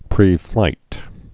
(prēflīt)